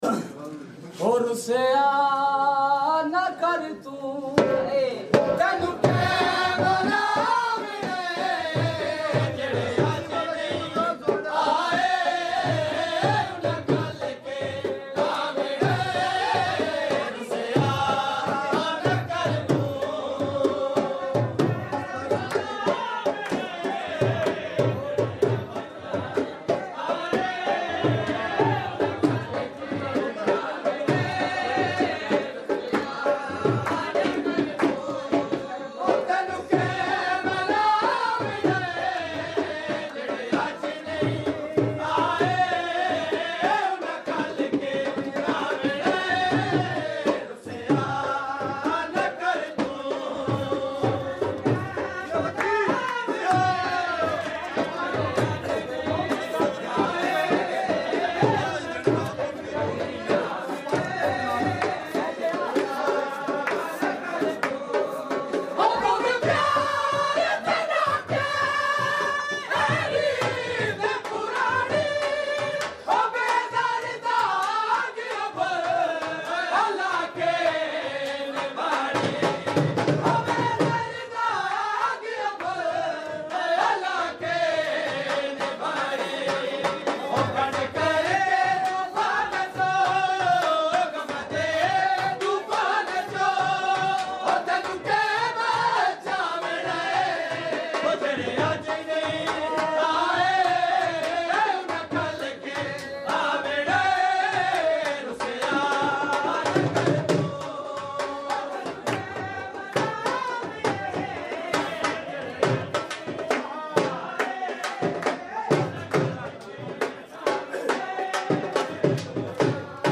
old Punjabi dhol geet chakwal area